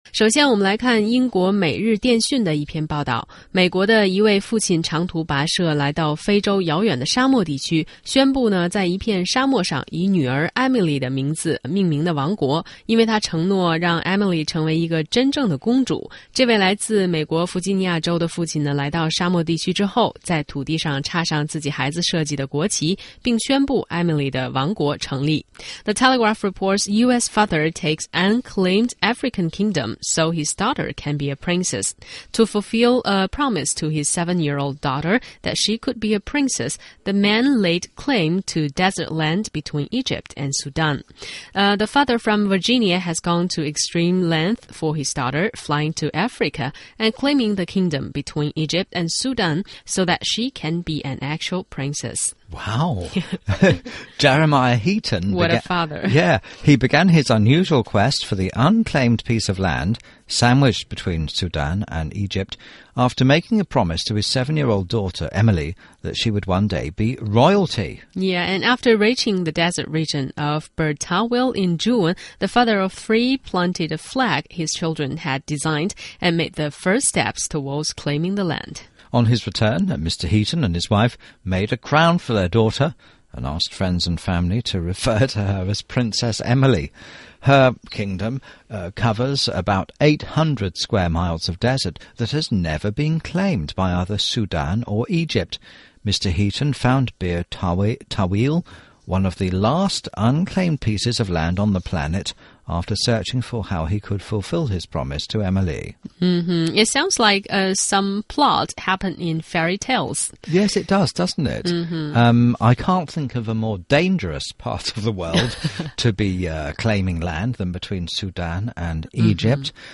在线英语听力室双语趣听精彩世界 第43期:为圆女儿公主梦,美国父亲沙漠上建国的听力文件下载,《双语趣听精彩世界》栏目通过讲述中外有趣的故事，来从不同的角度看中国、看世界，是了解大千世界的极好材料。中英双语的音频，能够帮助提高英语学习者的英语听说水平，中外主持人的地道发音，是可供模仿的最好的英语学习材料,可以帮助英语学习者在轻松娱乐的氛围中逐渐提高英语学习水平。